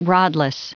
Prononciation du mot rodless en anglais (fichier audio)
Prononciation du mot : rodless